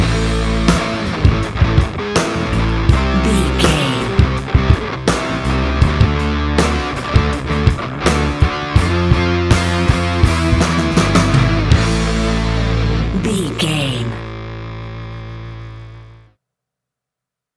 Ionian/Major
hard rock
heavy rock
distortion